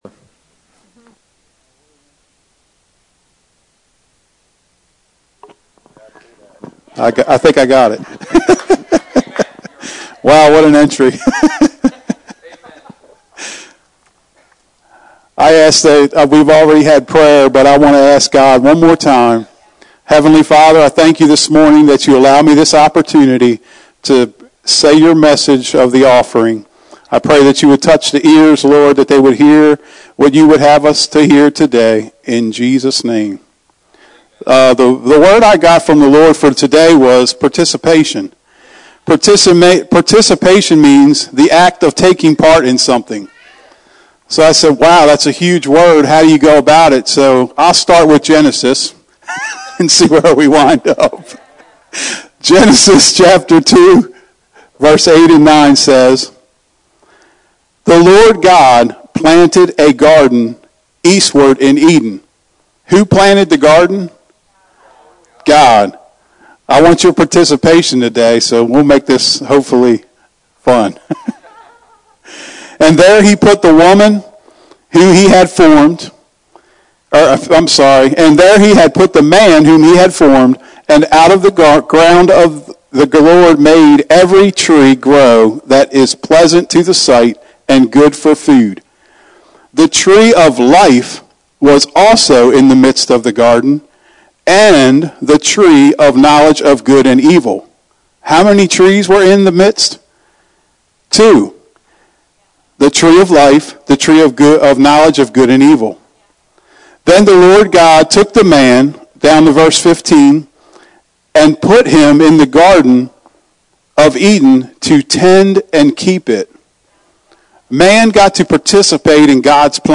Sermons | New Freedom Church